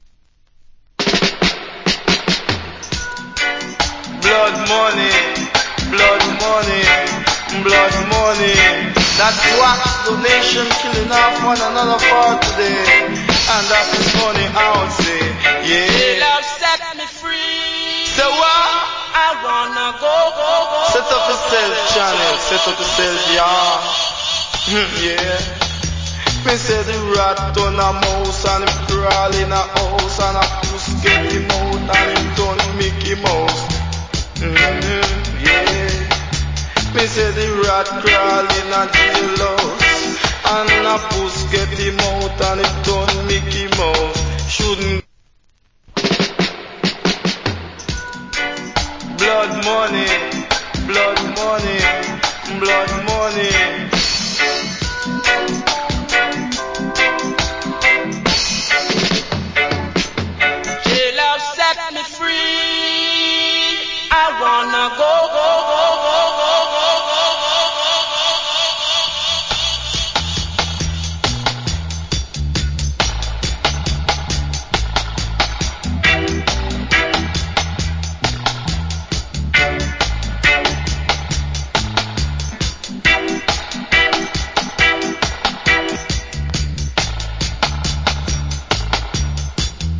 Killer DJ.